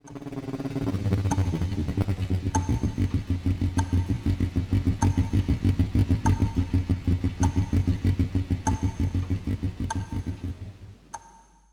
Sound Design
The sound creates a vague auditory experience at this stage, making the listener feel as if they are in an isolated environment, symbolizing the individual’s resistance to reality and psychological stagnation. The vague background sound and short sense of blankness in the sound strengthen the stagnant state of emotion.